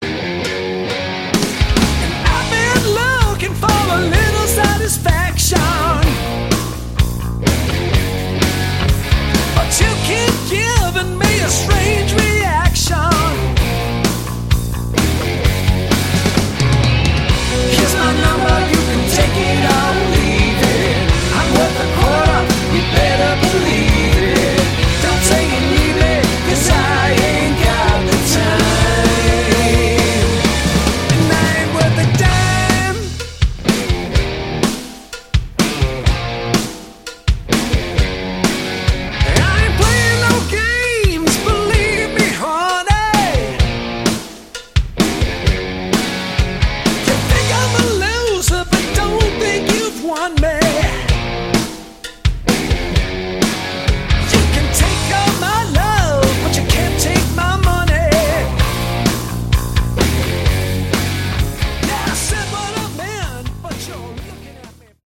Category: Hard Rock
vocals, guitar
guitar, backing vocals
bass, backing vocals
drums